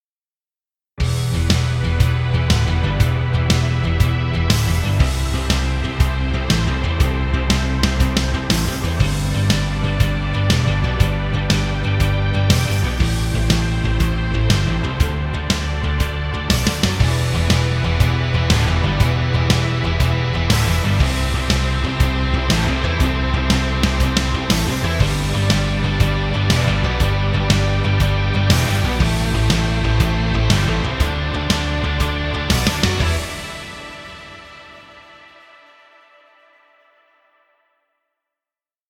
Rock music for video.
Royalty Free Music.